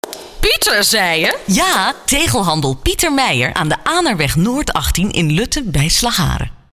Radiospotjes